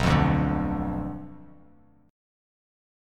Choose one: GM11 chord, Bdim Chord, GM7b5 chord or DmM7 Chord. Bdim Chord